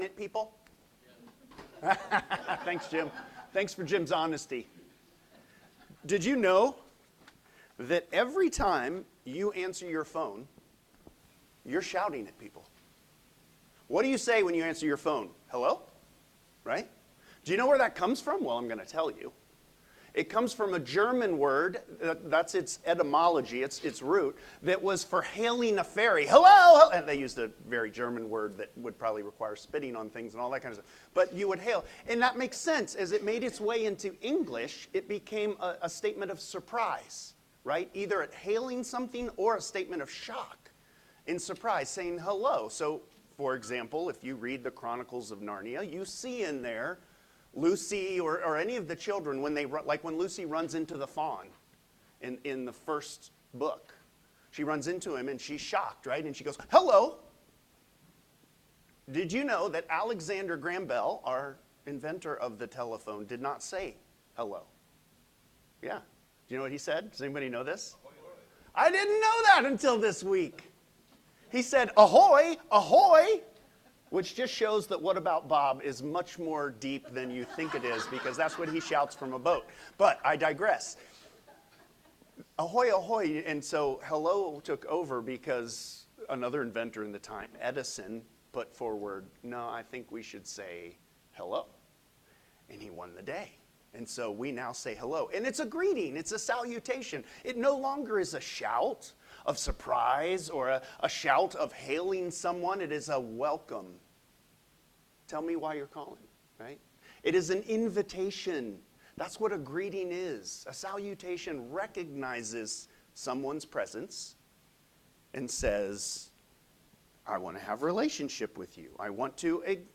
Sermons | Hope Presbyterian Church of Crozet
Current Sermon